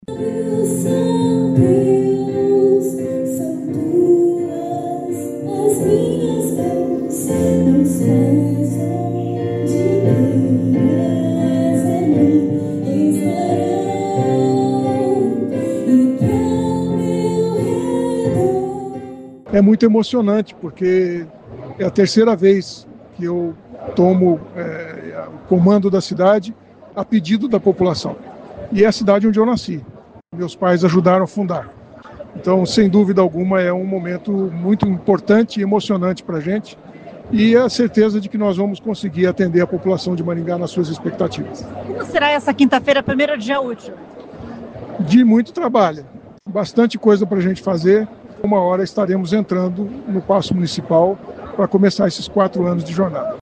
No discurso de posse, o prefeito inovou e convidou ao palco um dos candidatos que concorreram com ele às eleições